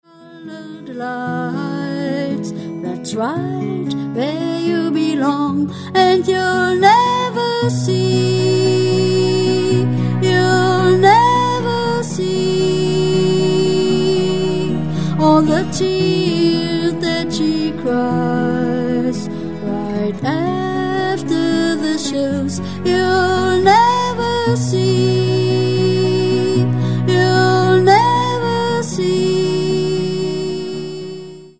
The song "The Star" is one of the first songs I recorded in a studio.